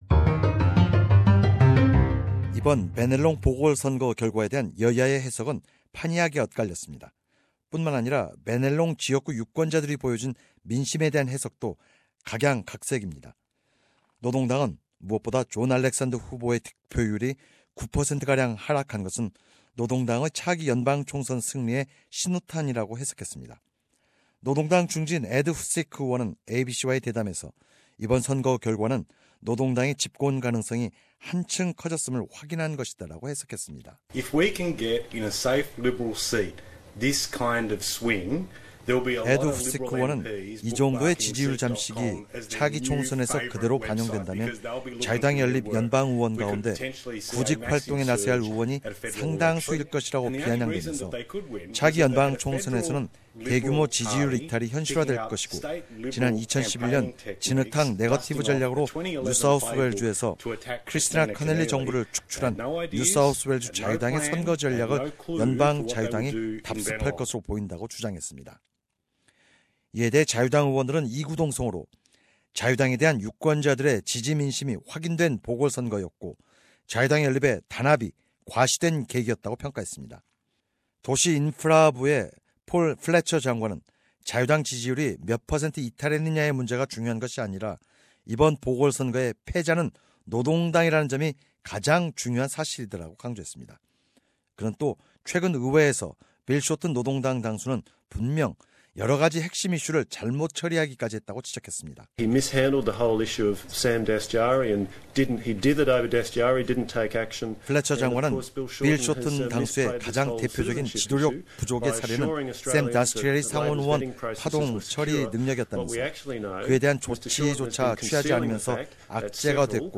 [포드캐스트 버튼을 클릭하시면 위의 내용을 오디오 뉴스로 접하실 수 있습니다.]